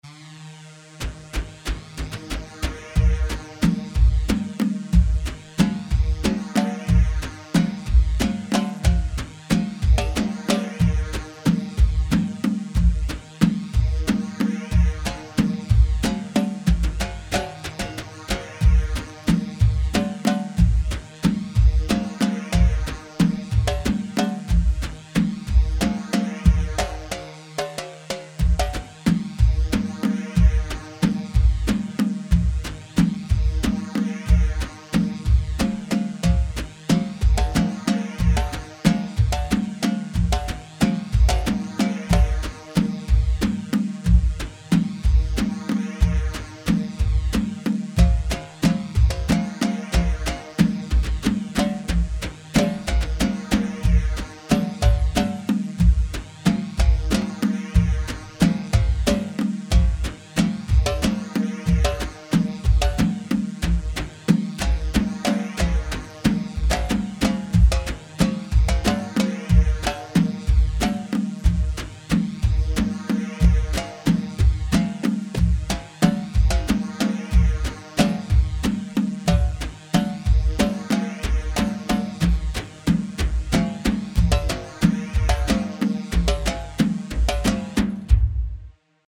Aarda 3/4 184 عرضة